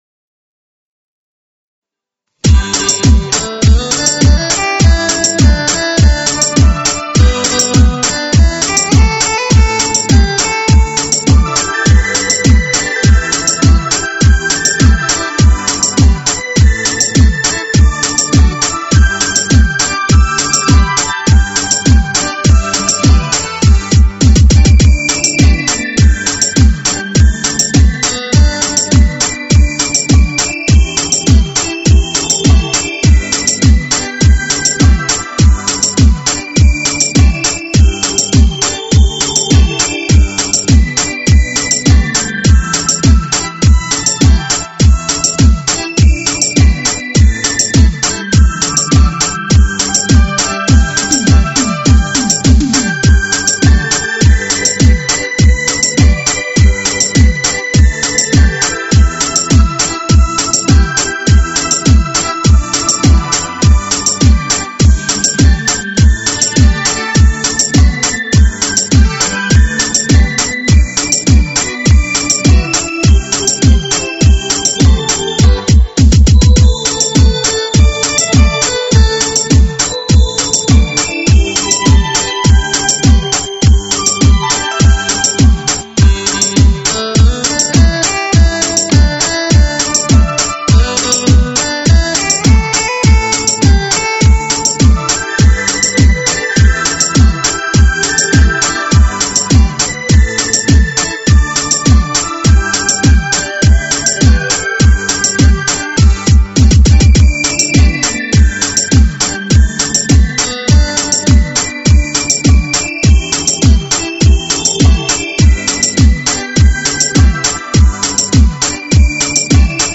舞曲类别：电子琴